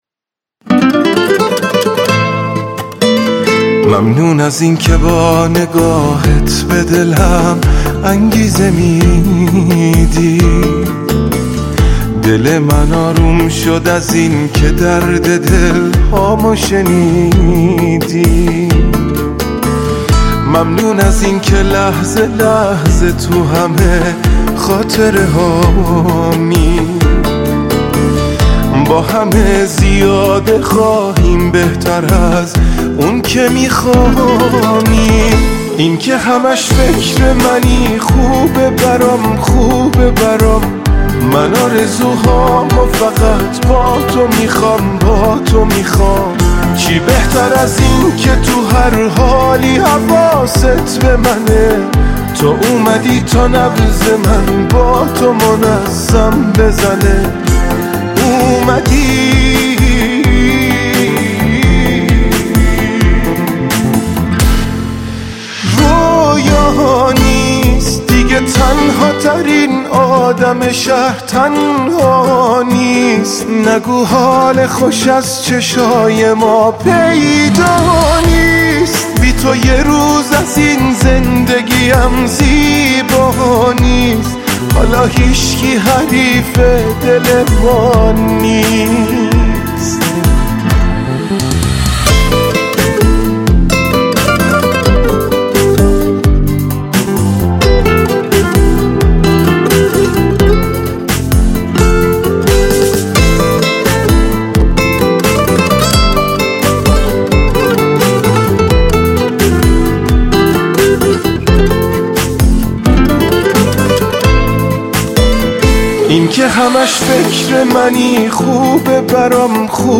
دانلود آهنگ شاد